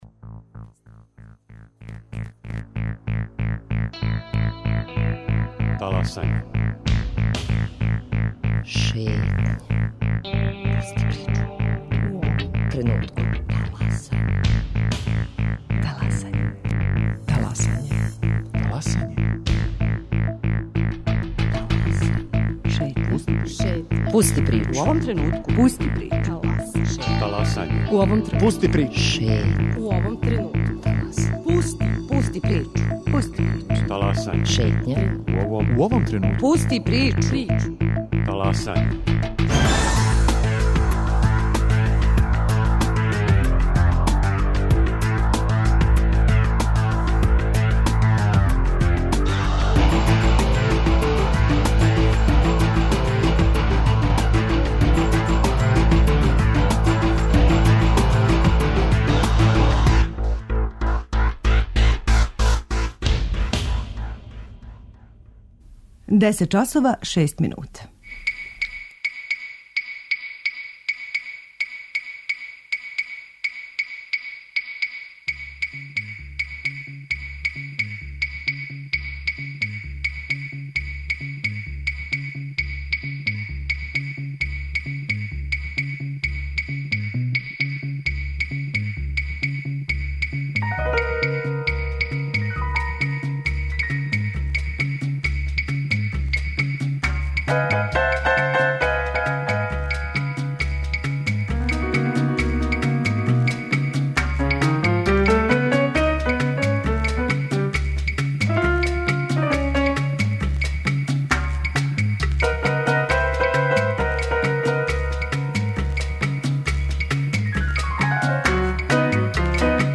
У току је 57. Међународни сајам књига у Београду са којег ће нам се телефоном укључити